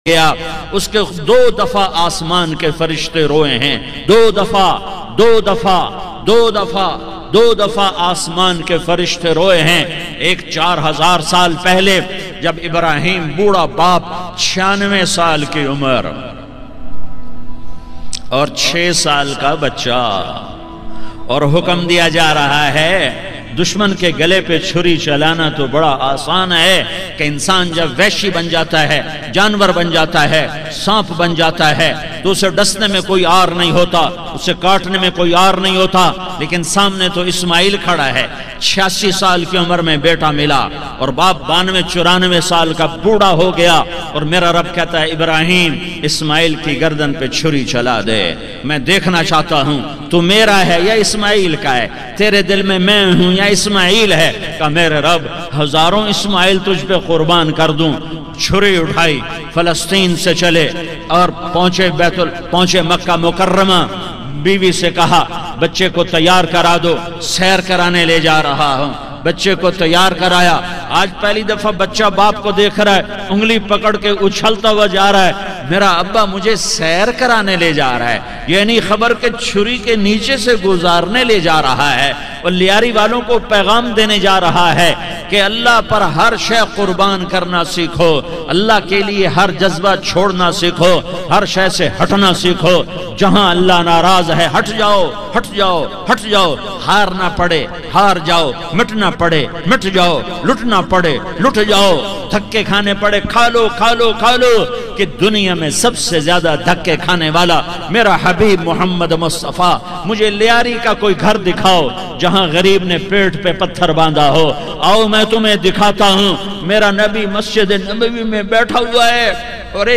Farishto (Angels) Ka Rona - Emotional Short Bayan By Maulana Tariq Jameel.mp3